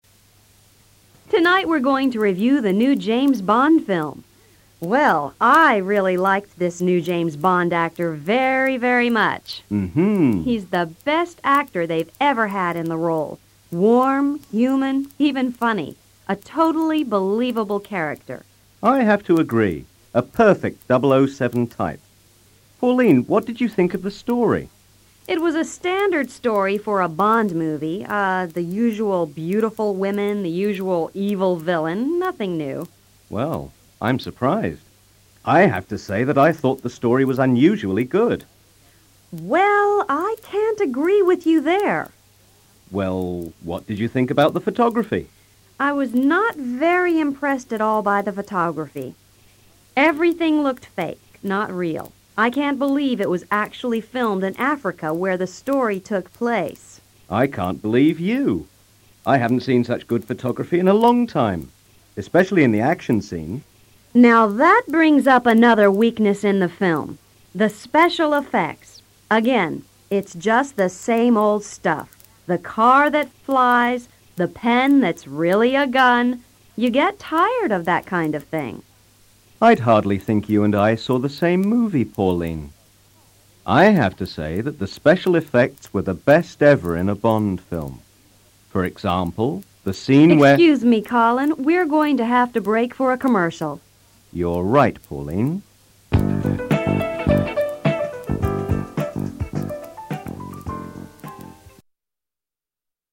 Two critics review a movie
Dos críticos analizan una película